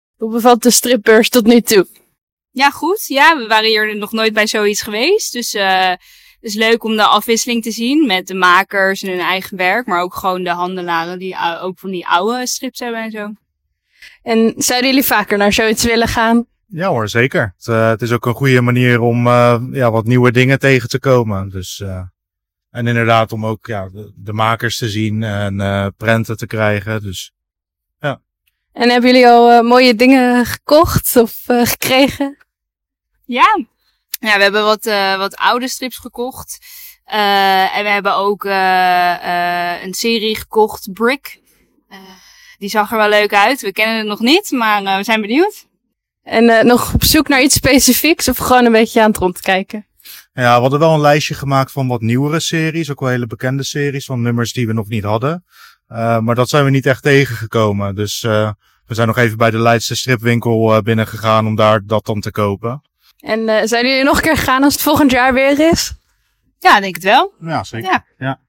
“We waren nog nooit bij zoiets geweest. Het is leuk om de afwisseling te zien van de makers en hun eigen werk, maar ook de handelaren die oude strips aanbieden,” zeggen twee enthousiaste bezoekers.
11-7-25-Stripbeurs-bezoekers.mp3